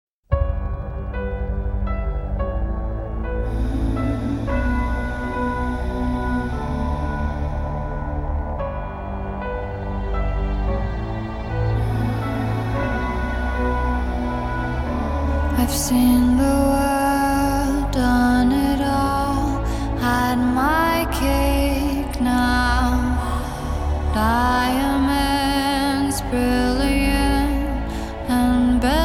мелодичные
спокойные
alternative
нежные
baroque pop
Хорошая, спокойная и нежная